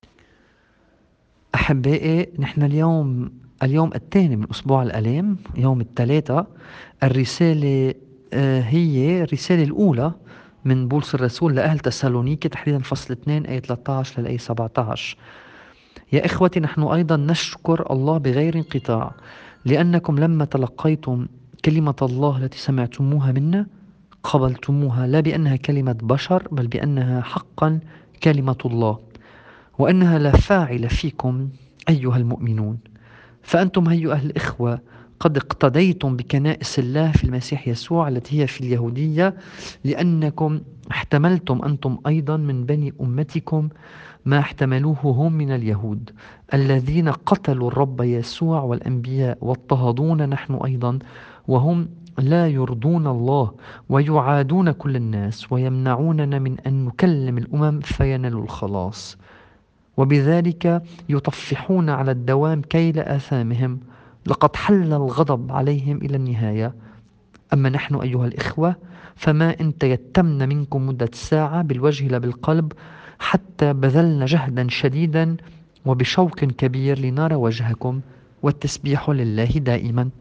الطقس الماروني